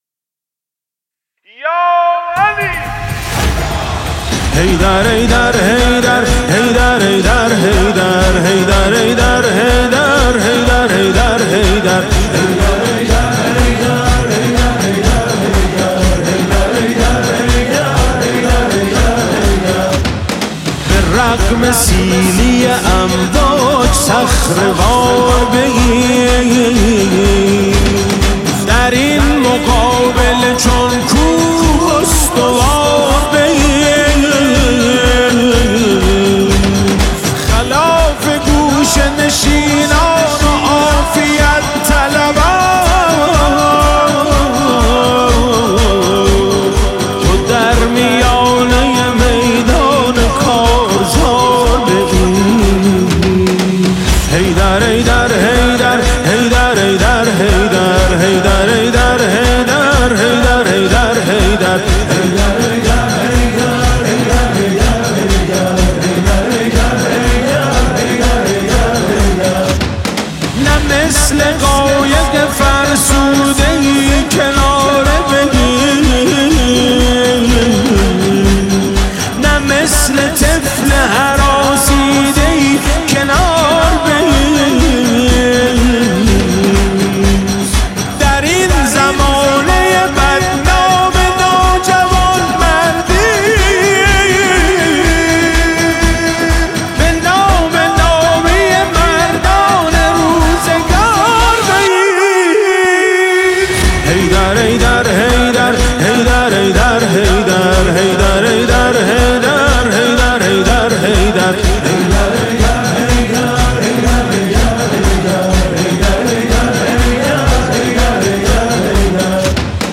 حماسی خوانی مداحان برای ایران/ "اینجا ایران امام حسینه"
بعد از پایان هر بیت حیدر حیدر گفتن جمعیت بلند می‌شود و حالا رمز «حیدر» شده، رمز مقاومت ملت.